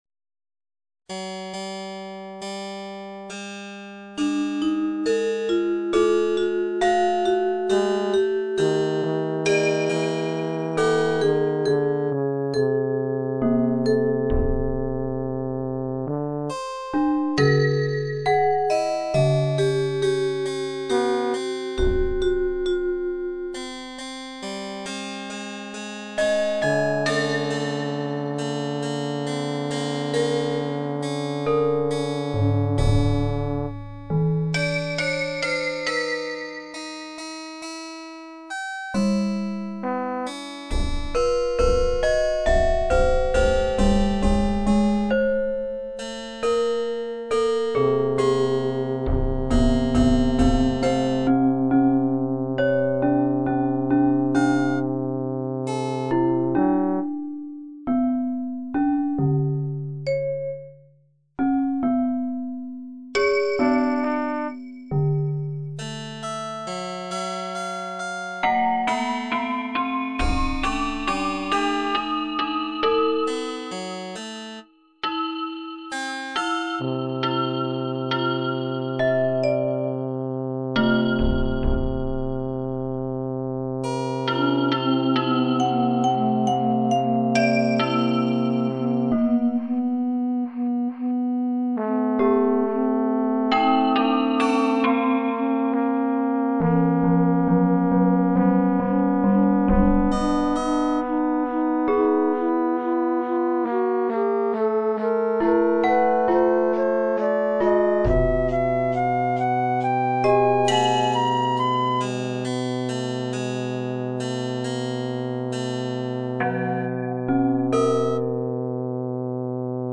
Esempi di musica realizzata con il sistema microtonale